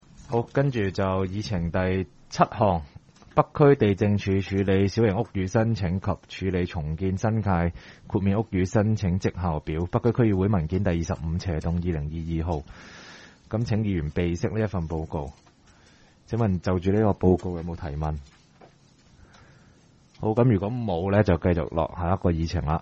区议会大会的录音记录
北区区议会第十二次会议
北区民政事务处会议室